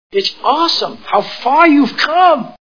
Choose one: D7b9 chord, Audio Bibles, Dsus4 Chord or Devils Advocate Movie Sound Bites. Devils Advocate Movie Sound Bites